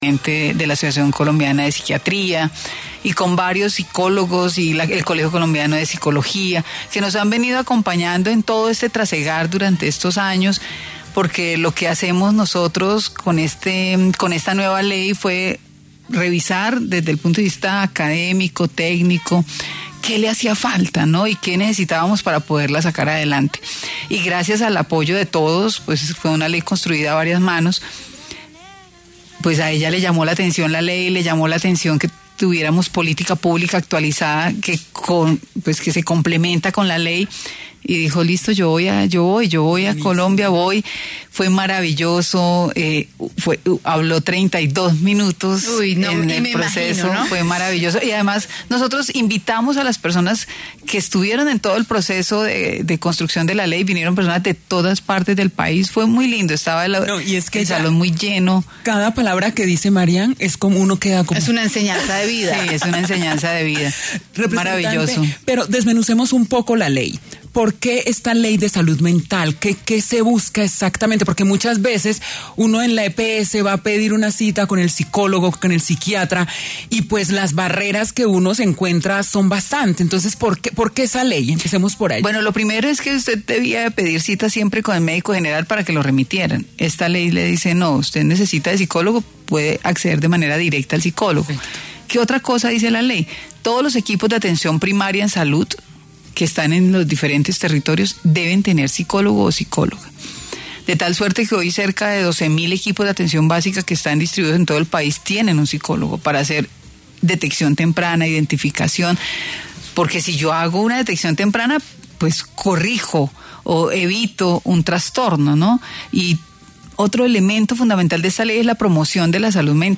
La Representante a La Cámara, Olga Lucía Velásquez, quién lideró la ley de la salud mental, habló en Mujeres W sobre el deber de garantizar atención oportuna y eliminar los estigmas acerca de la psicología.